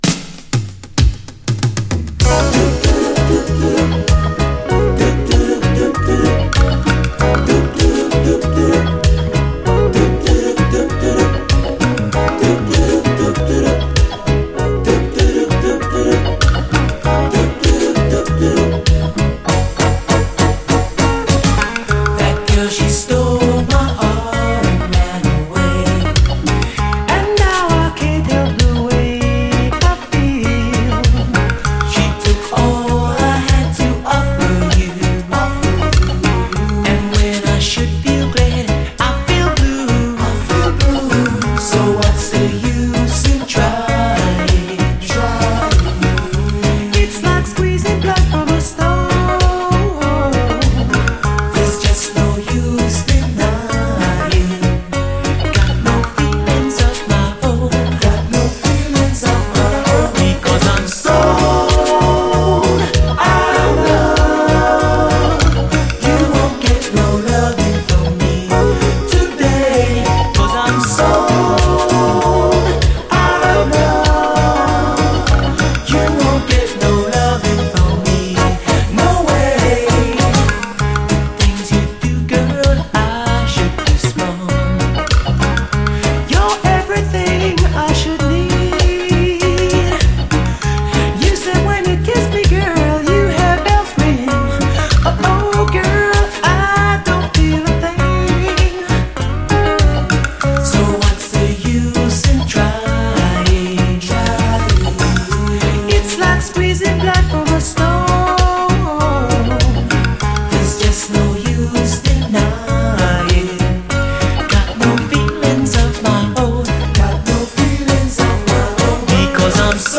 REGGAE
異常に涼しげなコーラス・ワークの虜となる傑作UKラヴァーズ！
異常に涼しげな彼らのコーラス・ワークに一発で虜となる最高チューン！